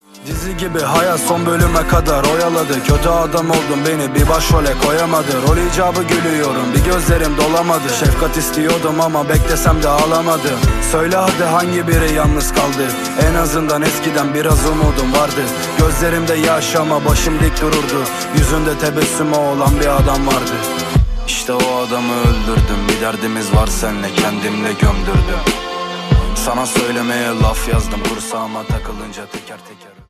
Rap/Hip-Hop